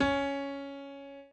b_pianochord_v100l1o5cp.ogg